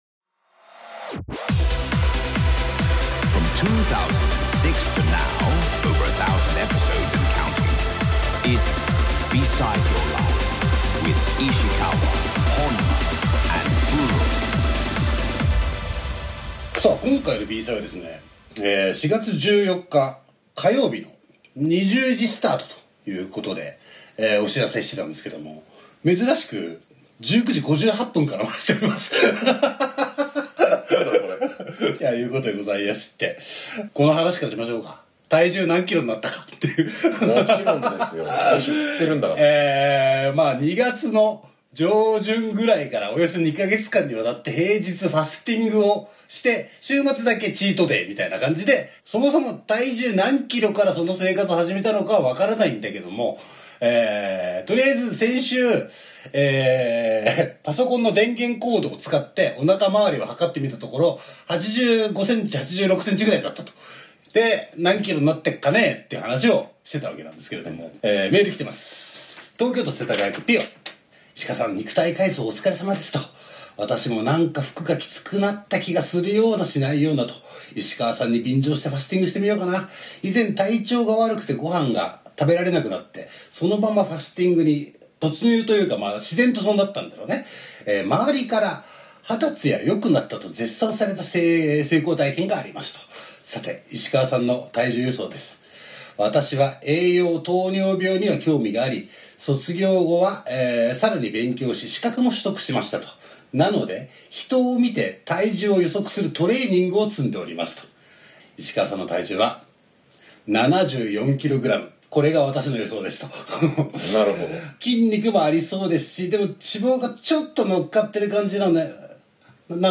4/14(火)夜収録。冒頭の数分間、ちょっと音質が悪めです。